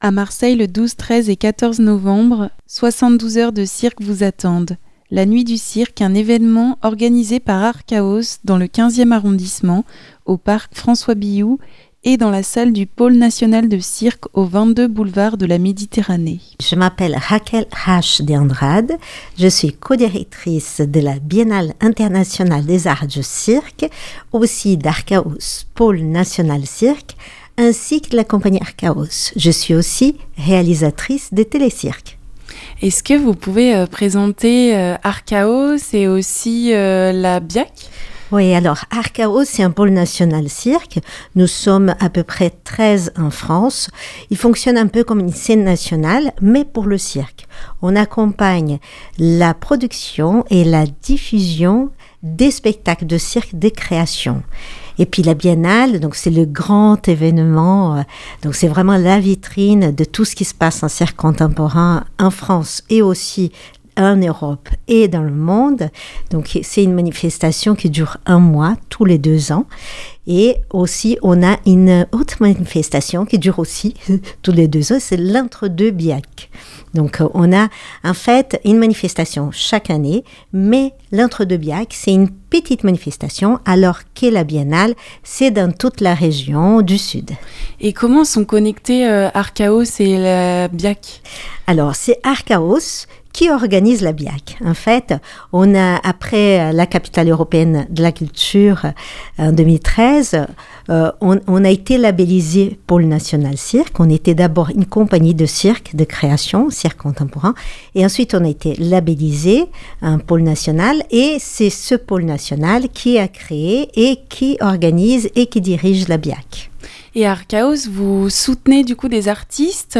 Interview Nuit du cirque (44.16 Mo)